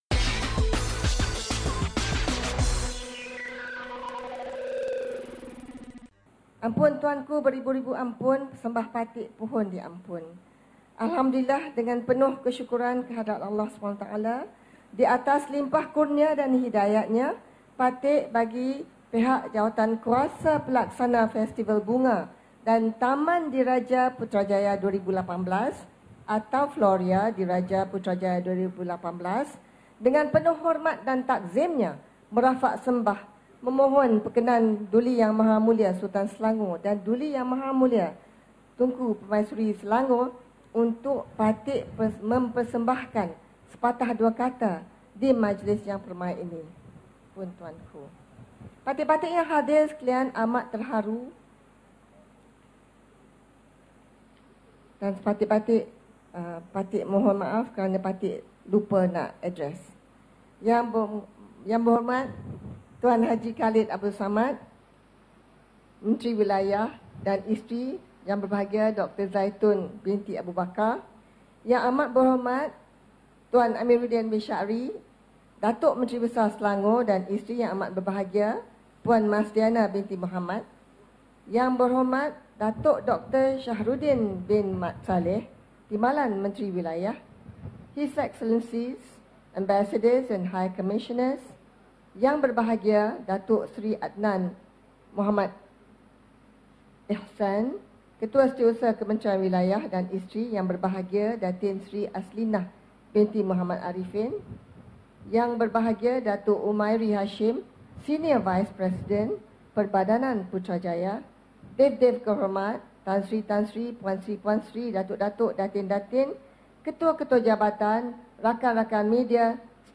Berikut merupakan ucapan oleh Timbalan Perdana Menteri Datuk Seri Dr Wan Azizah Wan Ismail ketika merasmikan Floria Putrajaya di Anjung Floria Event Centre, Presint 4, Putrajaya.